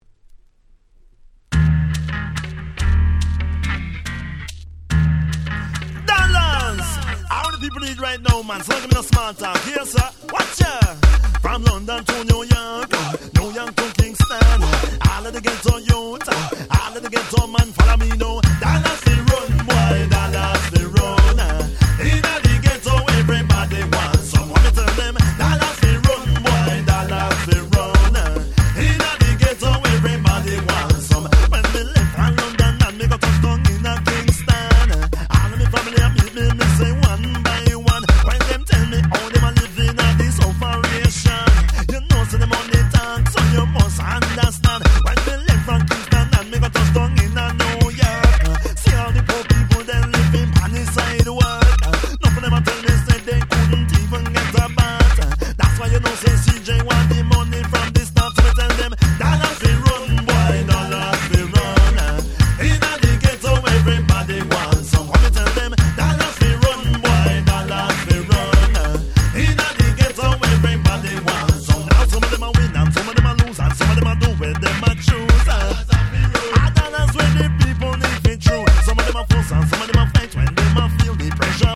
94' Smash Hit Reggae / Ragga Pop !!